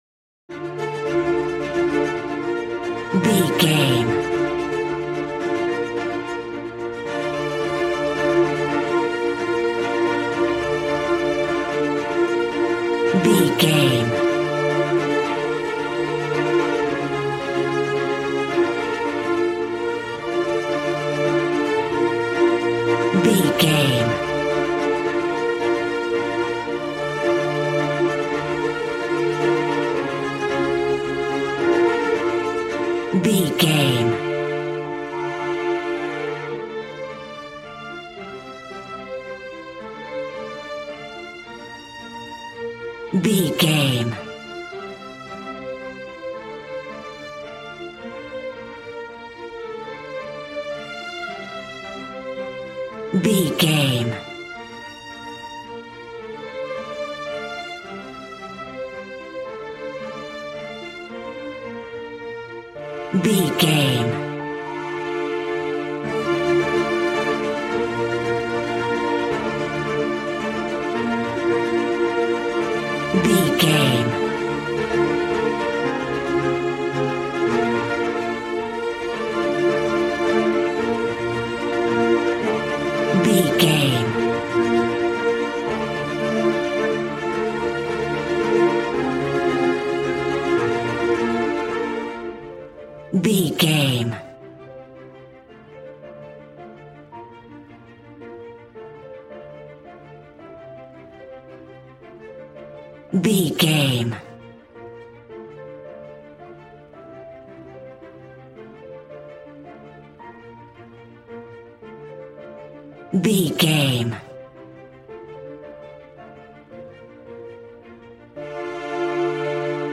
Regal and romantic, a classy piece of classical music.
Aeolian/Minor
D
regal
cello
violin
strings